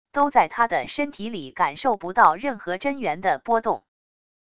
都在他的身体里感受不到任何真元的波动wav音频生成系统WAV Audio Player